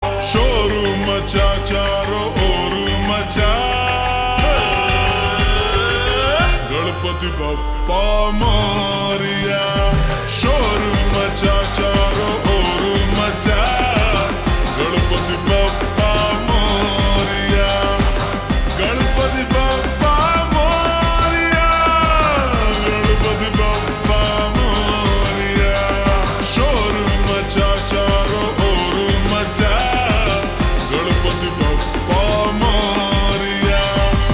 Slow Reverb Version
• Simple and Lofi sound
• High-quality audio
• Crisp and clear sound